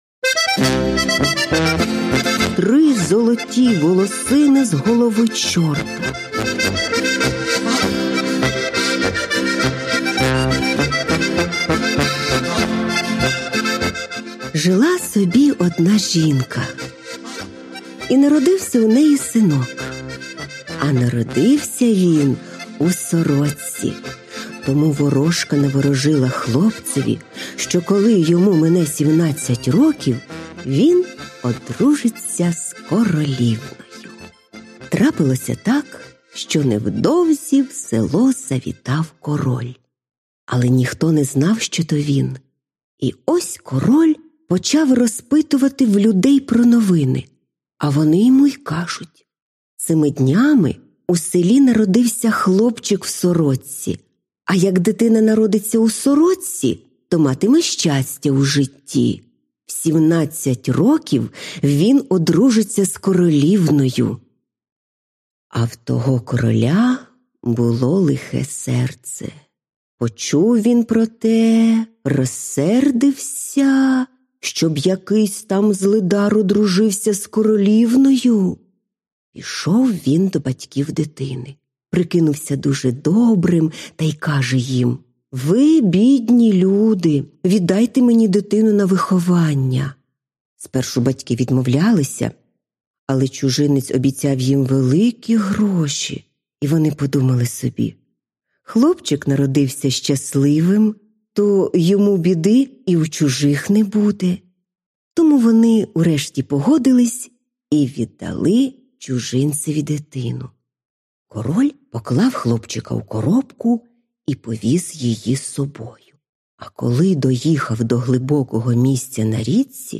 Аудіоказка Чорт із трома золотими волосинами